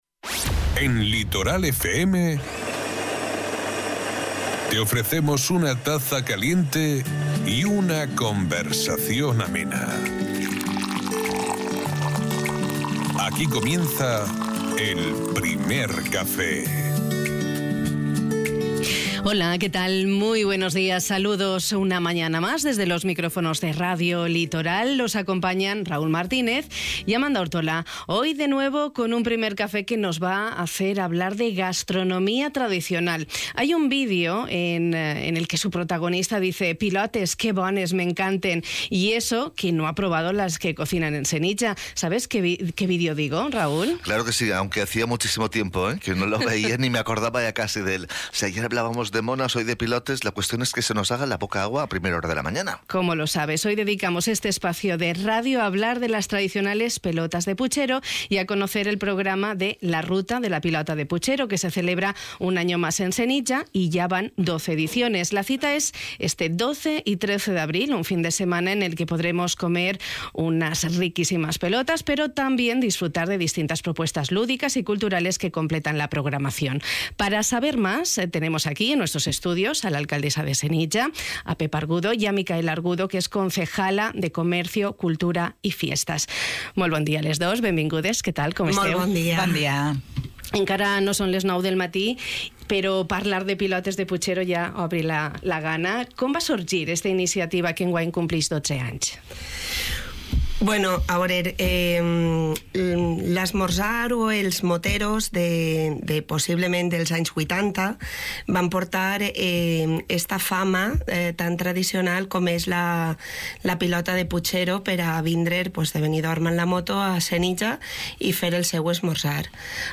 Nos lo han contado la alcaldesa de Senija, Pepa Argudo, y Micaela Argudo, concejala de Comercio, Cultura y Fiestas en la localidad.